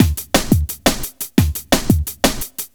Index of /neuro/Stanza/Drums/Drum Loops